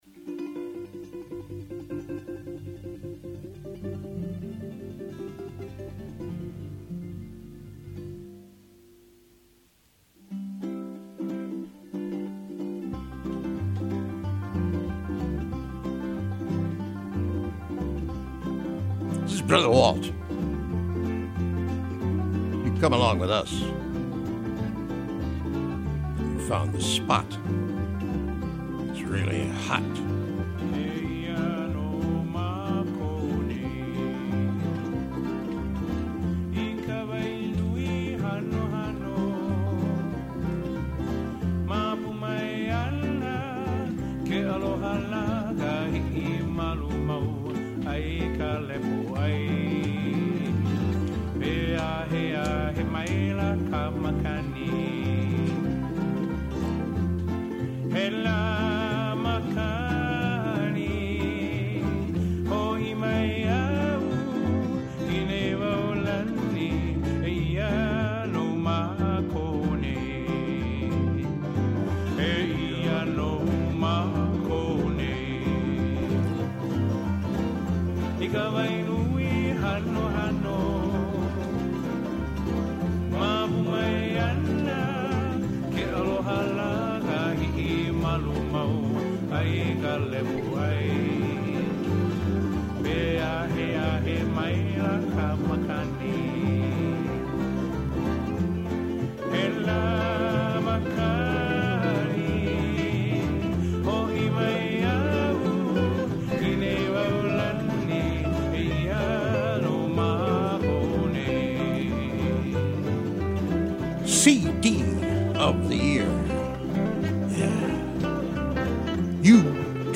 LIVE at the "Whodaguy" Jungle Studio
"Hitting the post" with live music is more fun than spinning pieces of plastic.